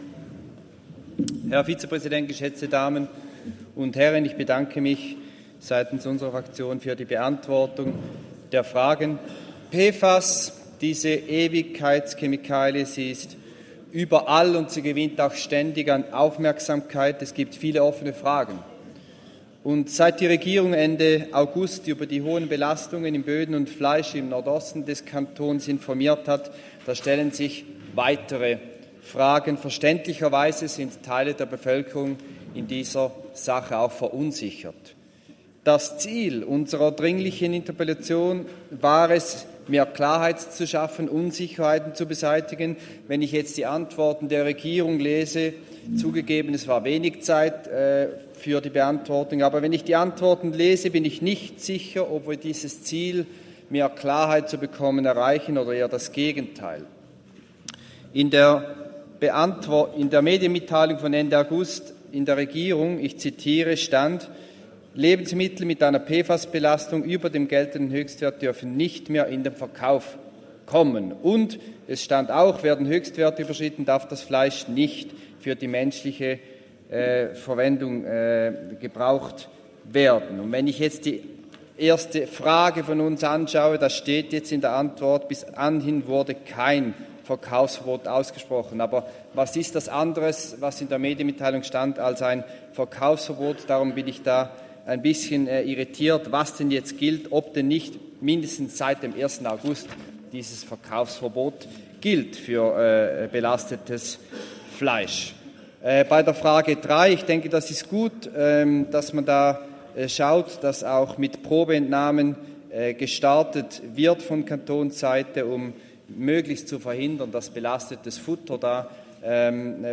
Session des Kantonsrates vom 16. bis 18. September 2024, Herbstsession